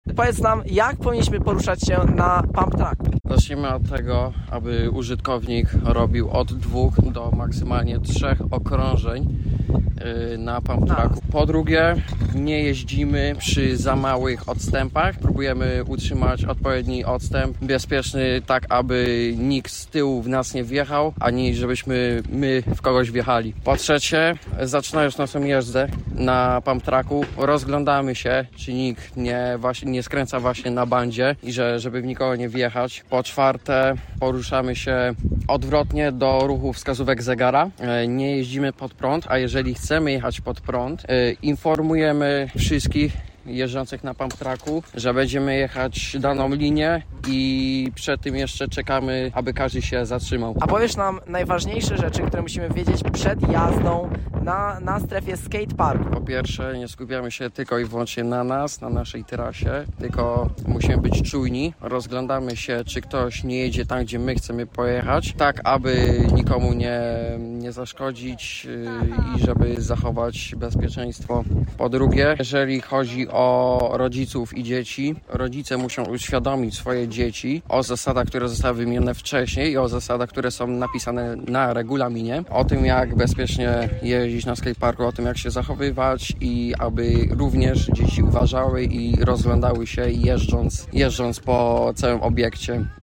Jak powinno się bezpiecznie korzystać z kompleksu? – radzi nam miłośnik jazdy na bmx’ie.